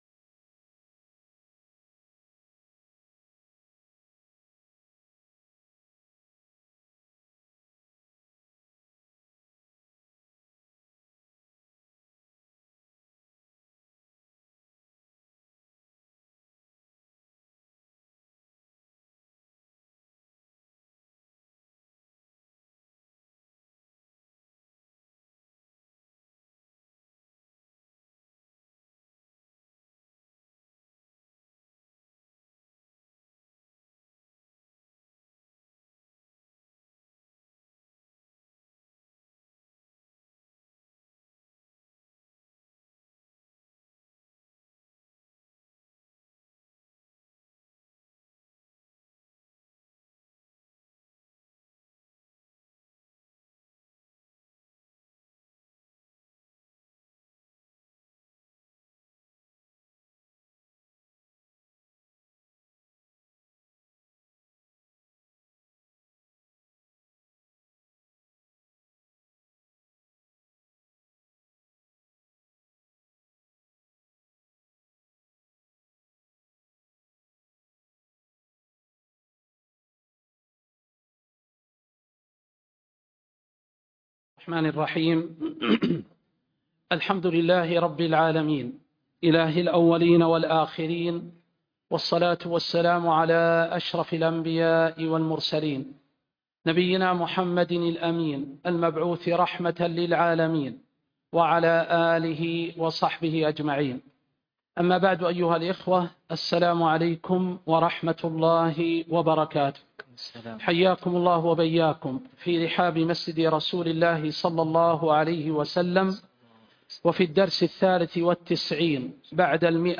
شرح التحبير في علم التفسير17 وكتاب بلوغ المرام 193